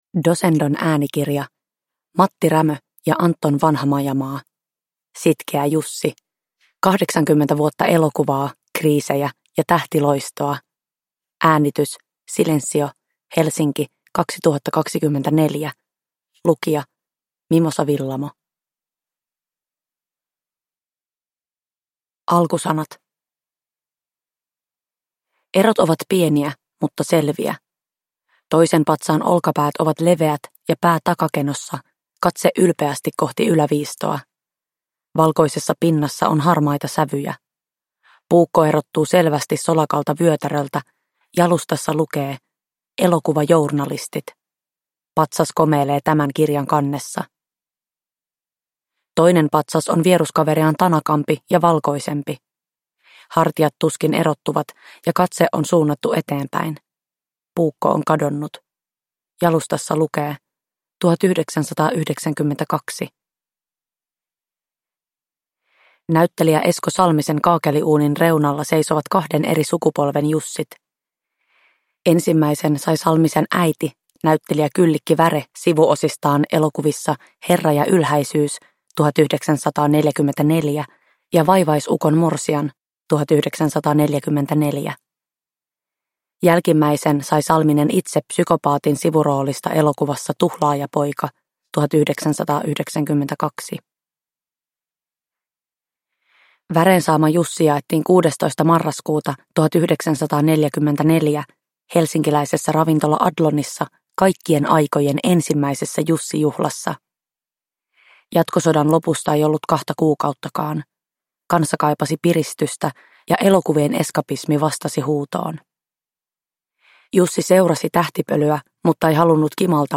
Sitkeä Jussi – Ljudbok